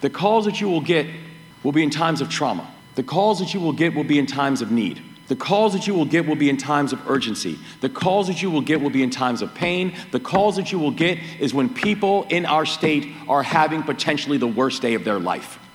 Moore Speaks at Police Graduation
The Maryland State Police held their 159th Academy graduation ceremony late last week with Governor Wes Moore as the guest speaker.